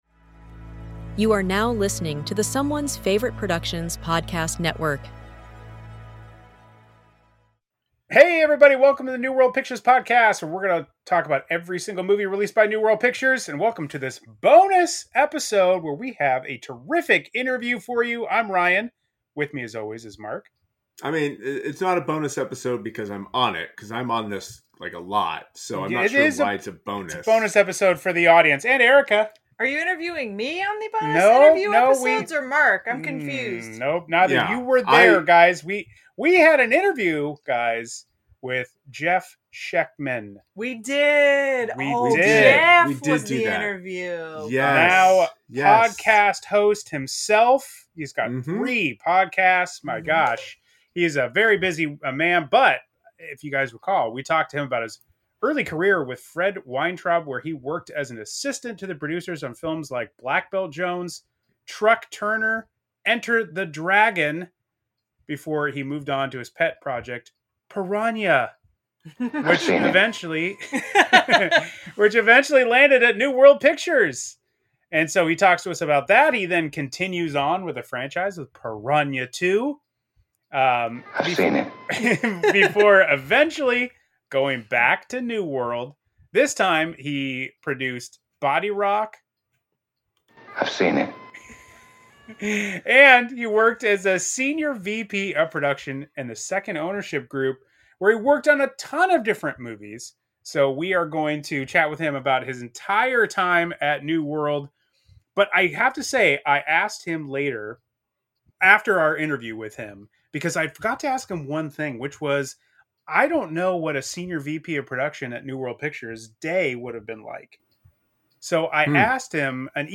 Bonus Episode: Interview